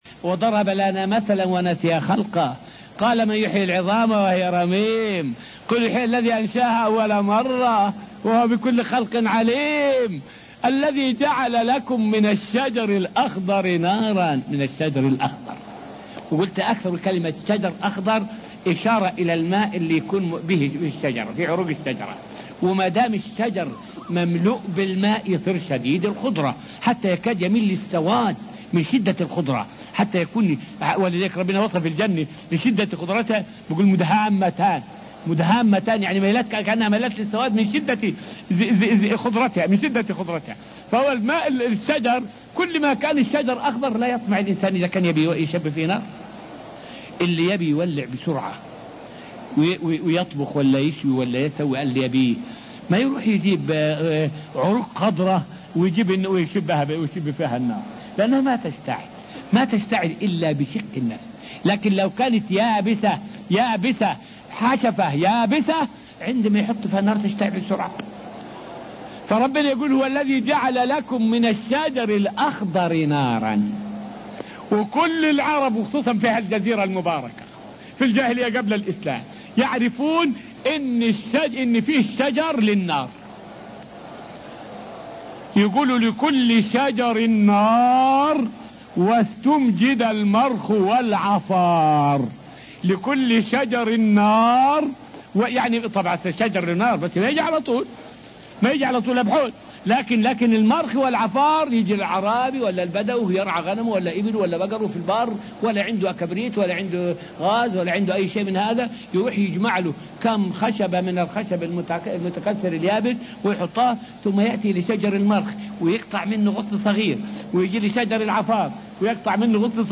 فائدة من الدرس الرابع والعشرون من دروس تفسير سورة البقرة والتي ألقيت في المسجد النبوي الشريف حول معنى قوله تعالى {الذي جعل لكم من الشجر الأخضر نارًا}.